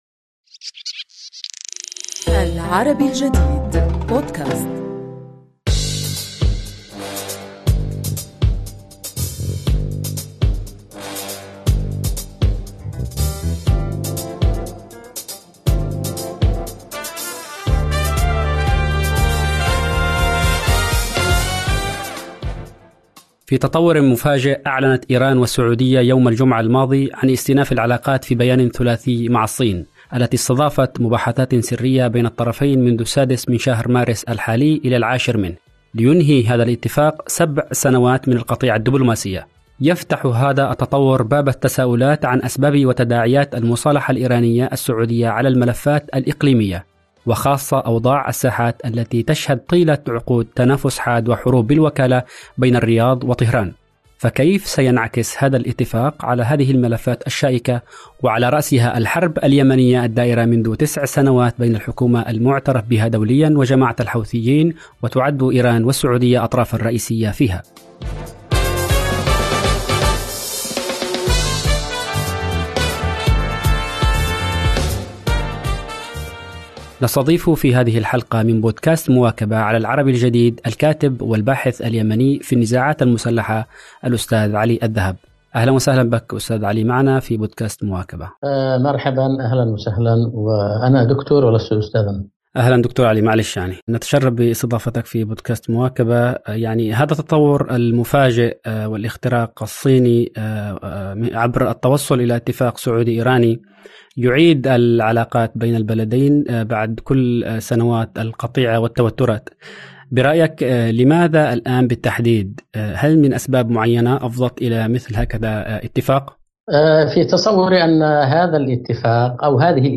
فكيف سينعكس هذا الاتفاق على هذه الملفات الشائكة، وعلى رأسها الحرب اليمنية الدائرة منذ تسع سنوات؟. نستضيف في هذه الحلقة من بودكاست "مواكبة" الكاتب والباحث اليمني في النزاعات المسلحة